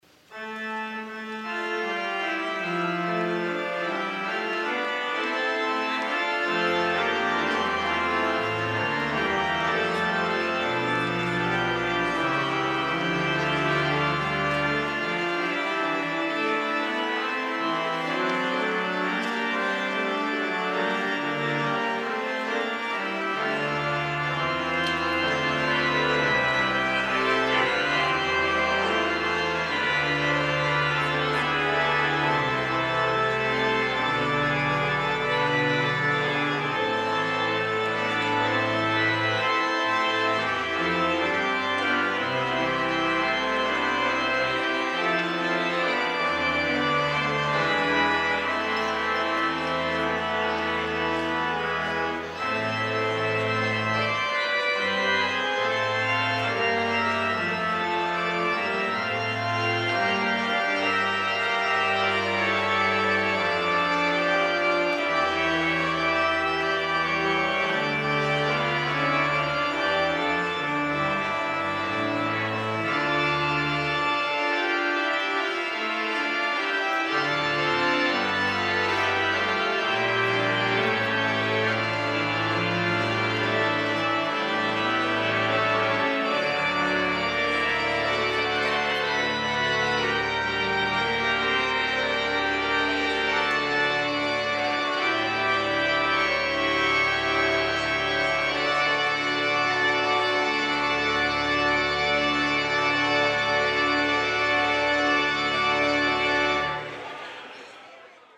organ